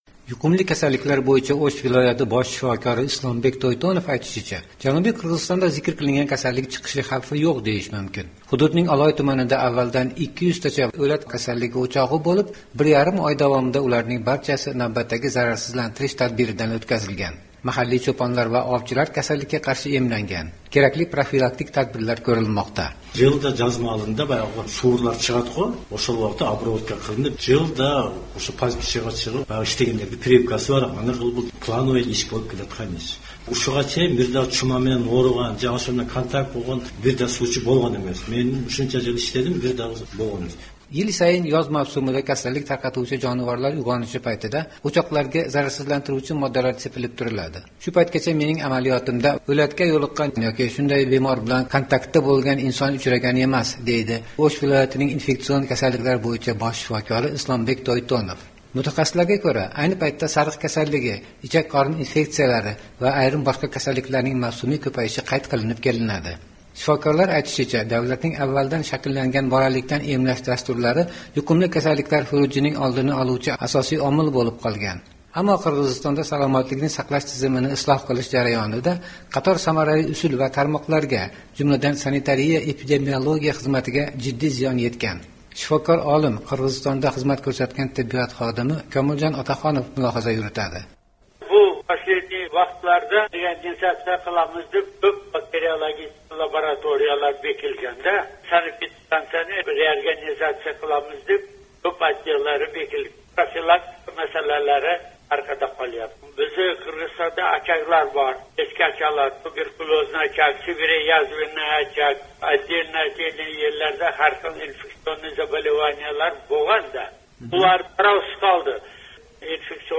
Mahalliy mutaxassislar bilan suhbatni tinglang: